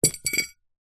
Звук упавшей металлической медали на ковер